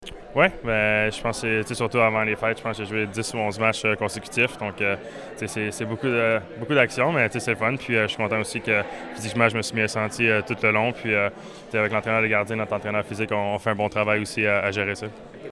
Présent comme co-ambassadeur lors du lancement de la campagne de financement d’Entraide Bécancour, mardi, Samuel Montembeault, a mentionné que l’équipe peut continuer sur cette lancée.